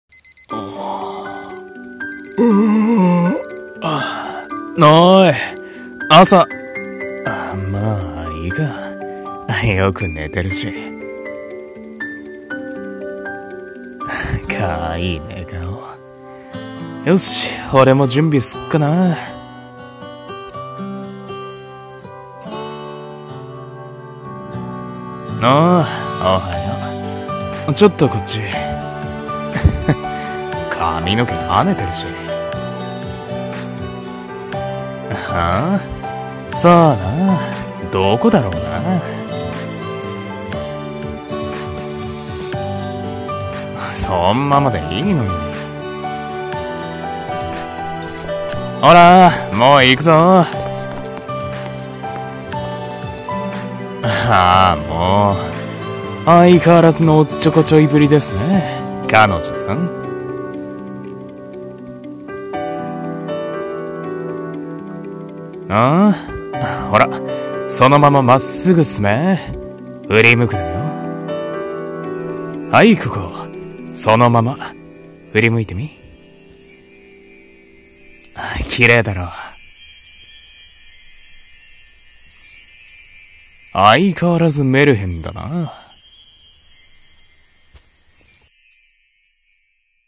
【声劇】 カップルの日常 コラボ歓迎